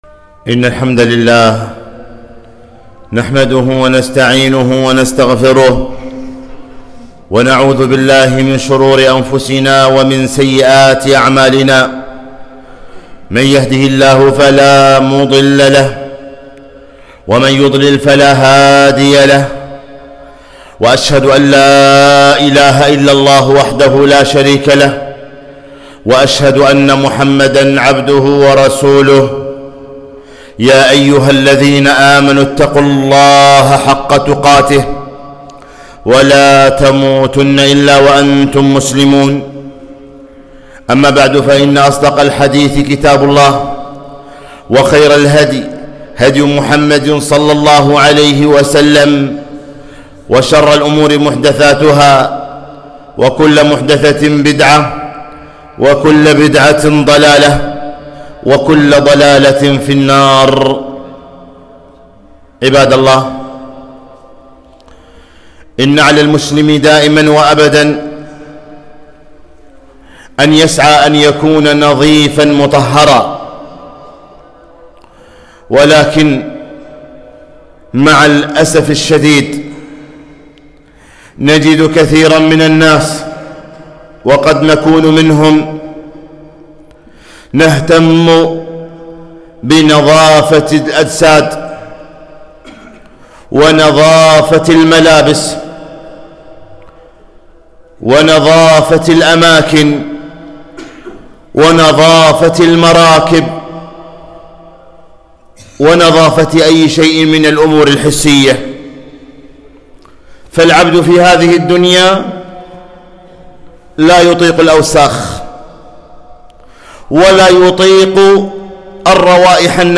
خطبة - منظفات القلوب